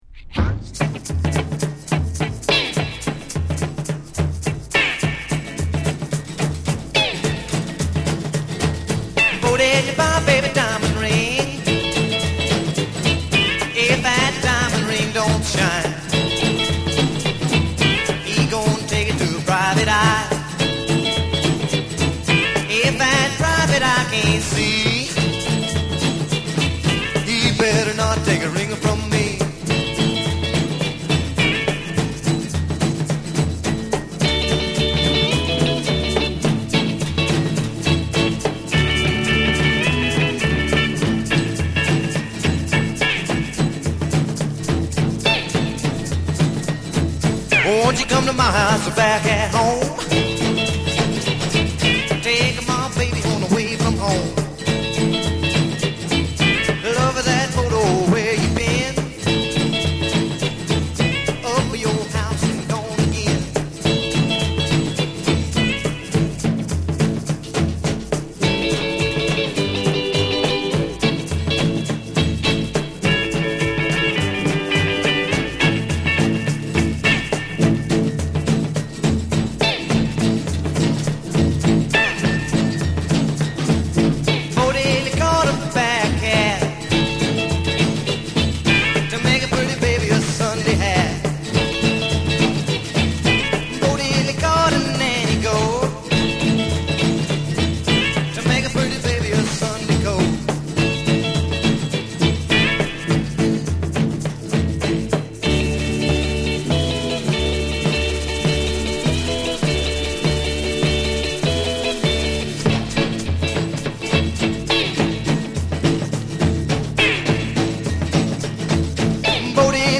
Genre: Rockabilly/Retro